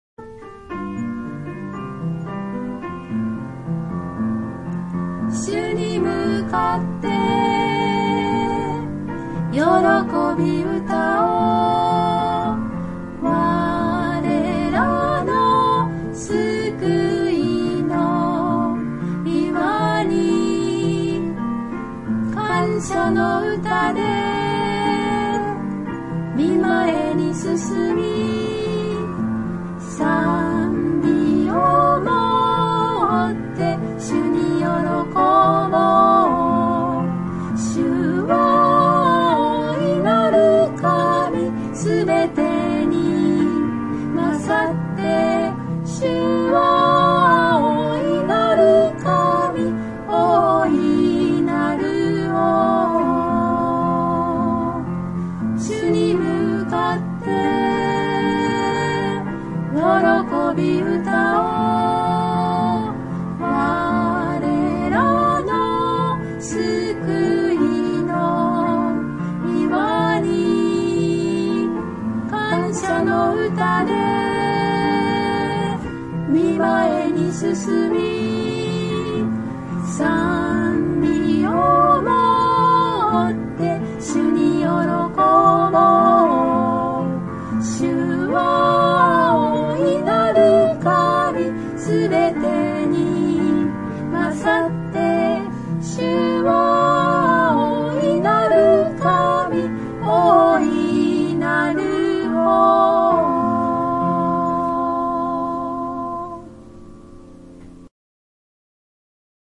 （徳島聖書キリスト集会集会員）